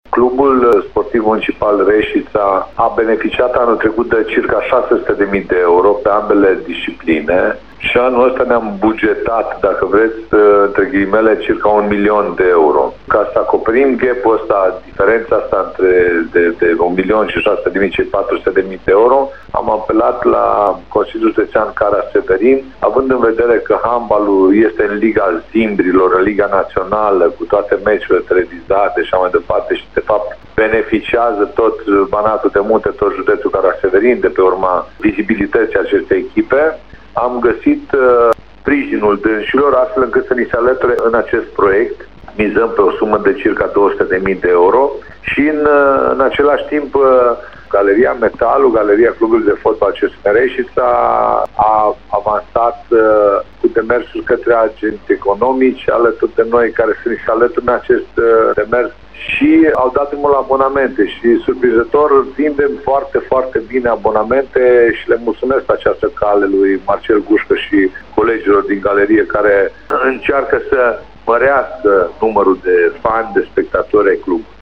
Într-un interviu la Radio Timișoara, primarul Ioan Popa a afirmat că bugetul va fi de aproximativ un million de euro, pentru ambele secții.
10-iul-7.05-Ioan-Popa.mp3